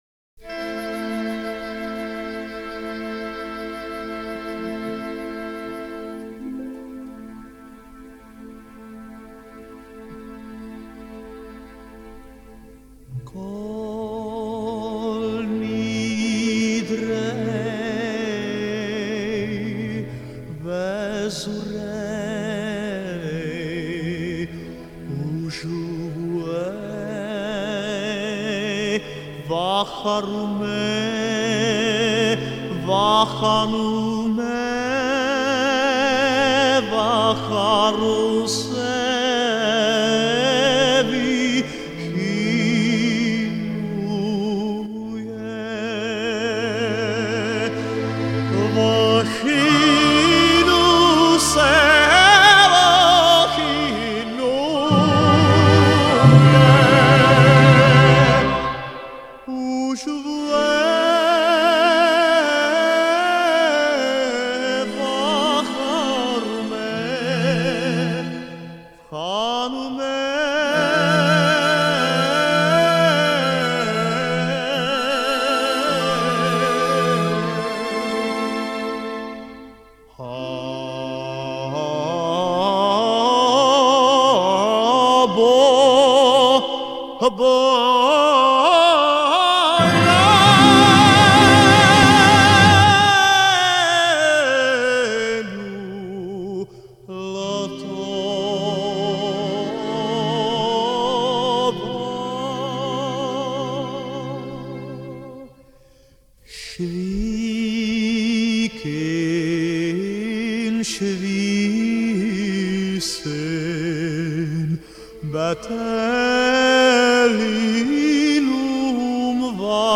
А как поет красиво!smile